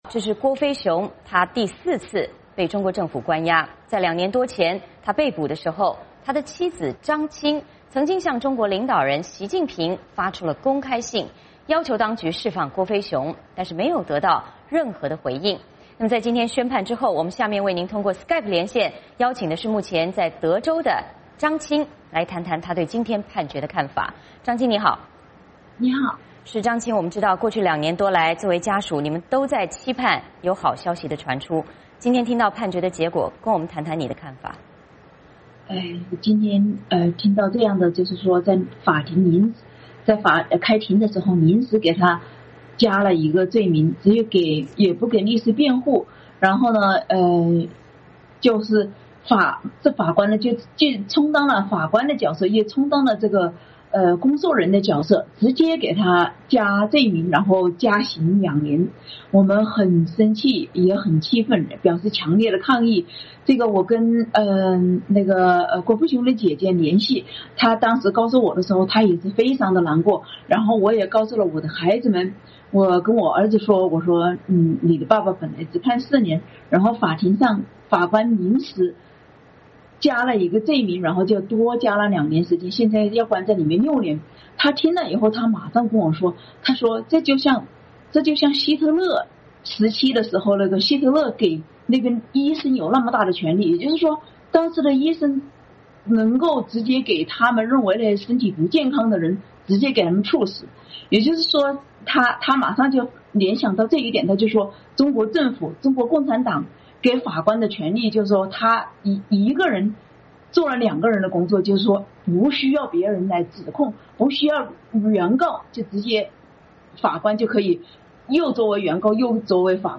下面我们通过SKYPE连线